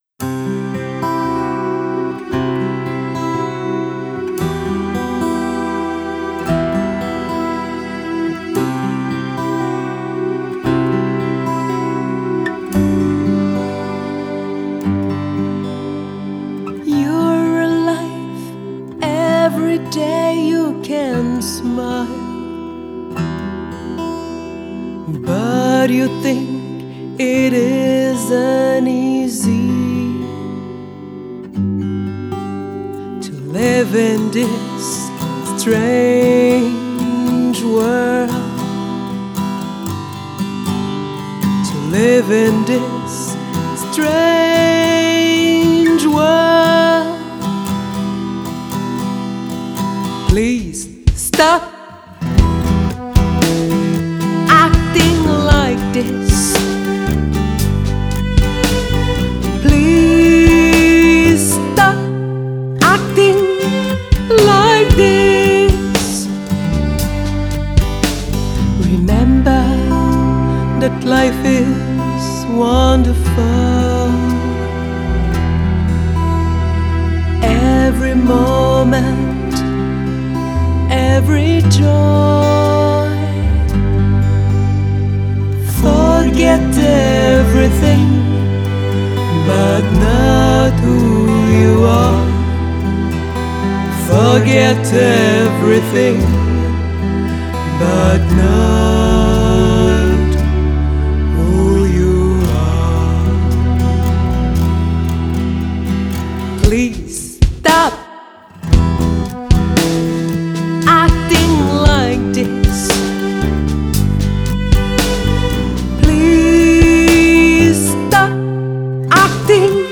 Genre: Folk, Pop